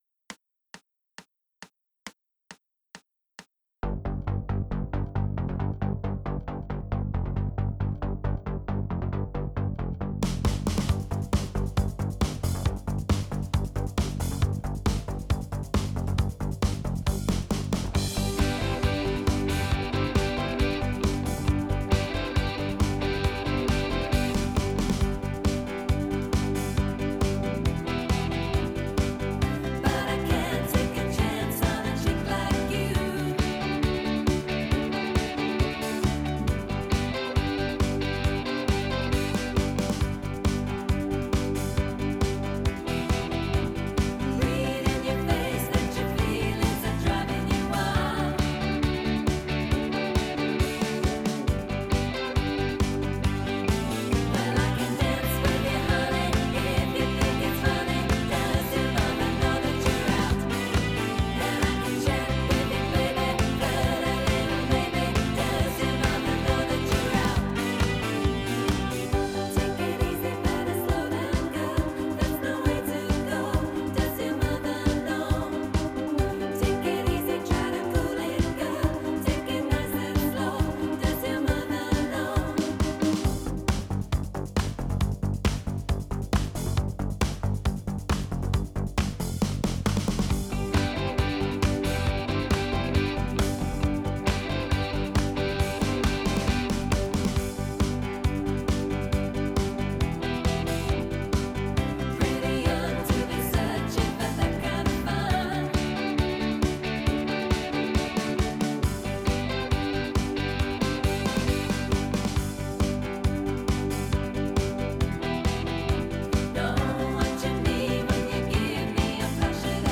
4 VOCAL